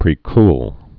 (prē-kl)